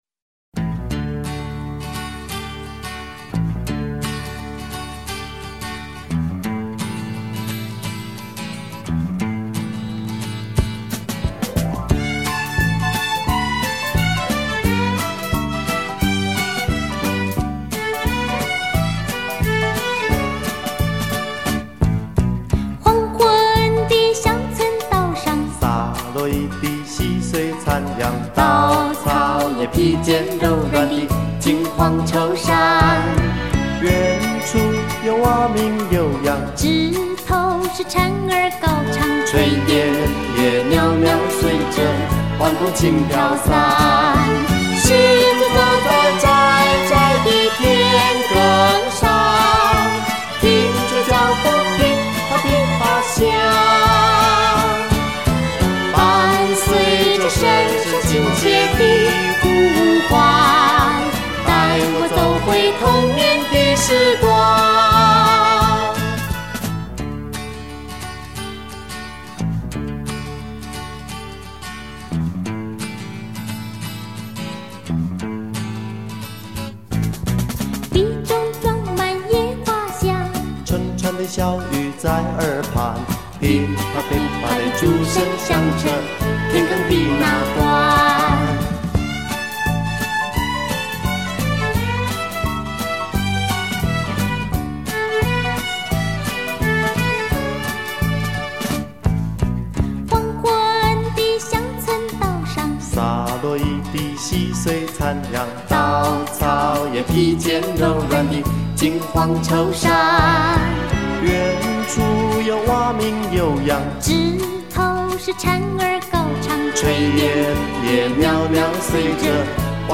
完整复刻音乐中独有的田野派曲风